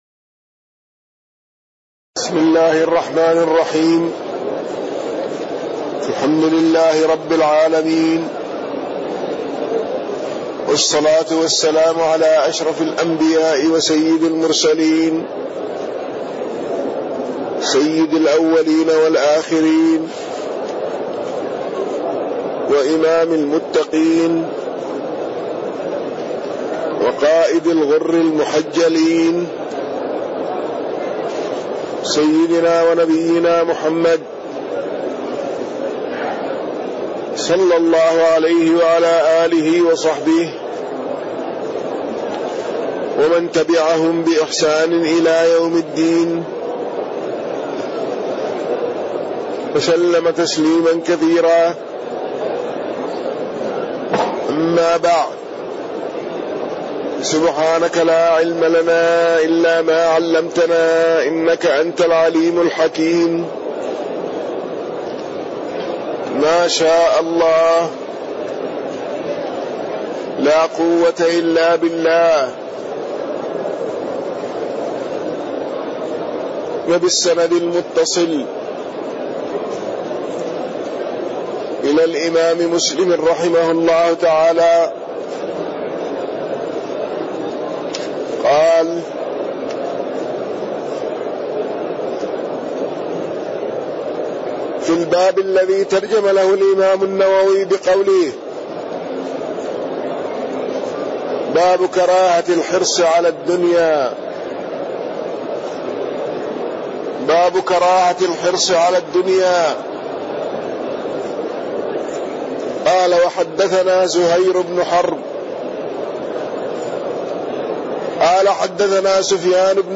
تاريخ النشر ٢١ رمضان ١٤٣٢ هـ المكان: المسجد النبوي الشيخ